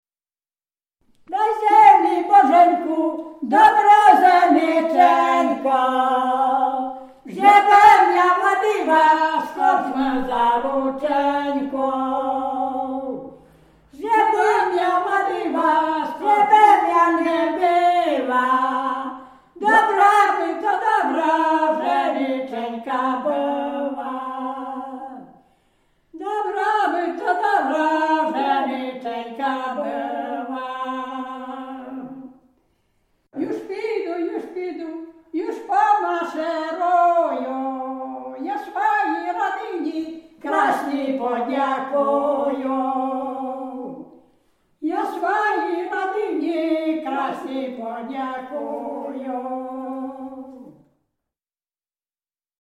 Dolny Śląsk, powat legnicki, gmina Kunice, wieś Piotrówek
Przyśpiewki
Łemkowie